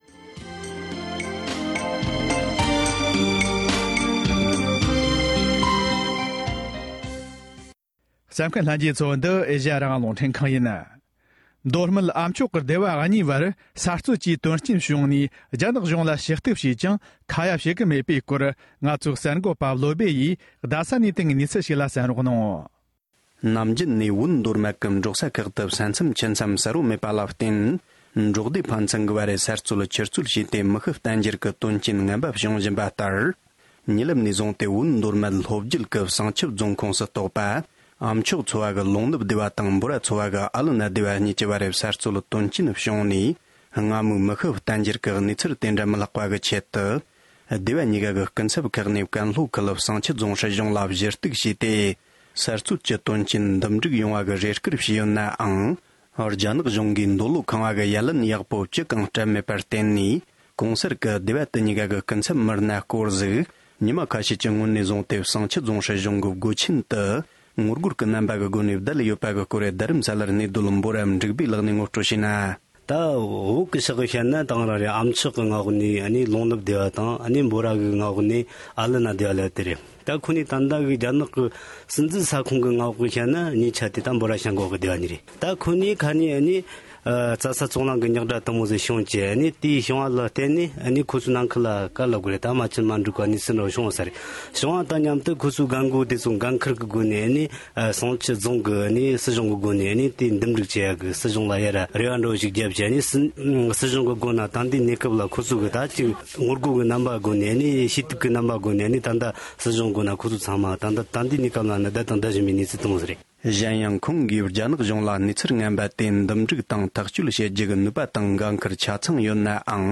སྒྲ་ལྡན་གསར་འགྱུར།
གསར་འགོད་པས་བཏང་བར་གསན་རོགས།